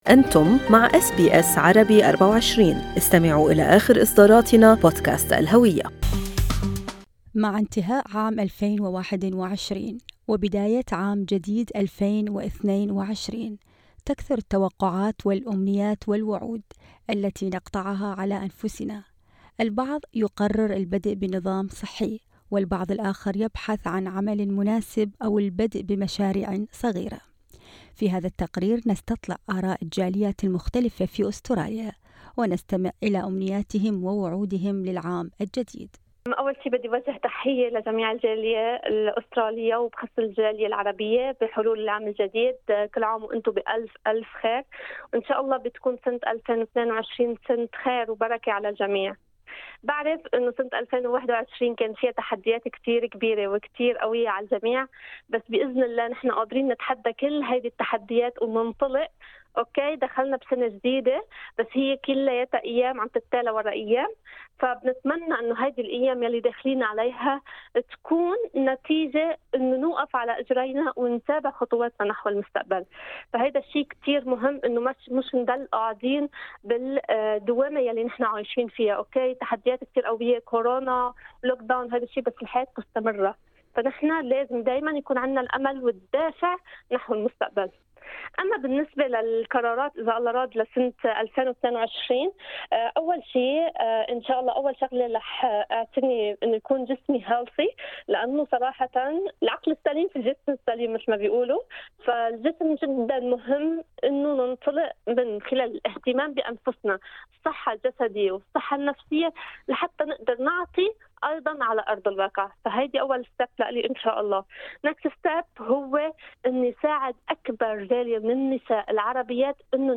وأجمع عدد من الأشخاص الذين التقيناهم في التقرير الذي أعدته أس بي أس عربي24 على انهم لن يتمنوا أو يتخذوا أي قرارات هذا العام خوفاً من عدم تحقيقها كما حصل في الأعوام السابقة، بحسب قولهم.